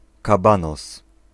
Ääntäminen
Vaihtoehtoiset kirjoitusmuodot 'long Synonyymit ache far high deep tall extended yearn lengthy prolonged a long time a long way pant desire ache for Ääntäminen : IPA : /ˈlɔŋ/ UK US : IPA : [lɔŋ] UK : IPA : /ˈlɒŋ/ Tuntematon aksentti: IPA : /ˈlɑŋ/